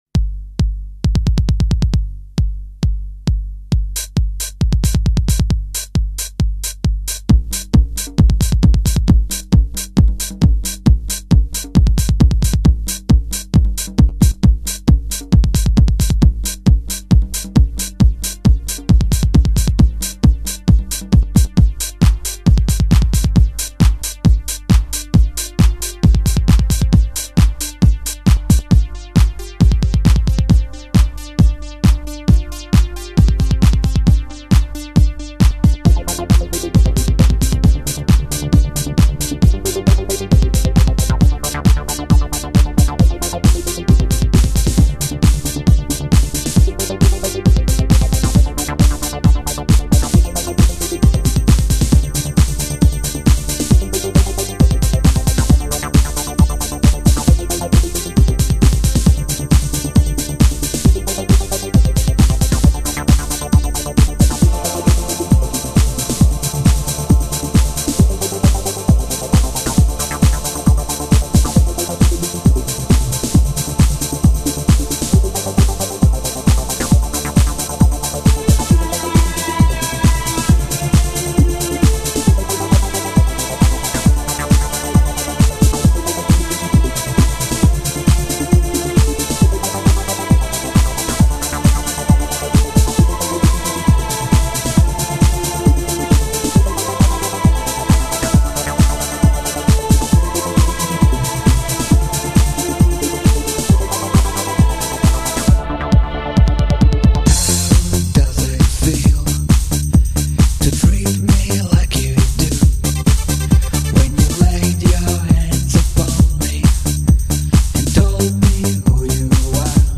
Retro 80’s Chill Mix